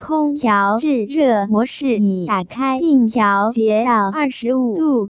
add chinese tts
S3_xiaole_speed0.wav